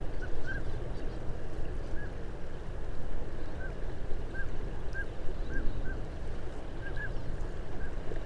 Northern Pintale